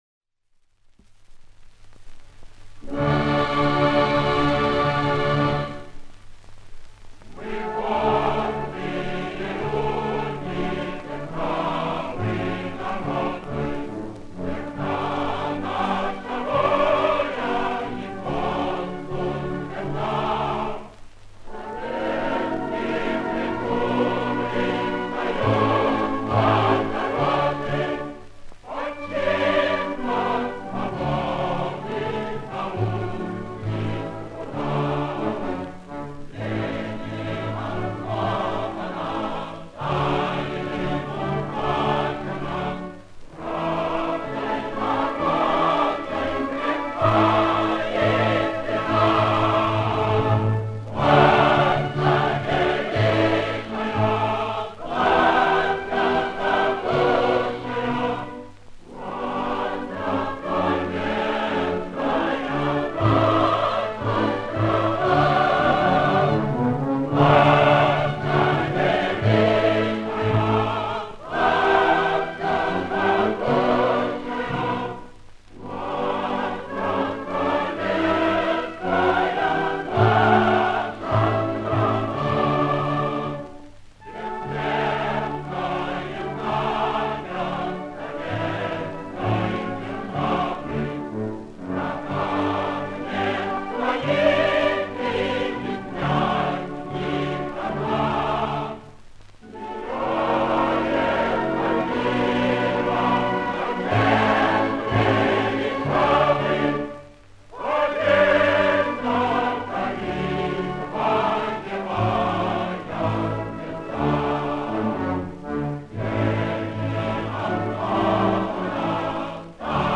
Песни о Родине
Медленная и величавая песня о Родине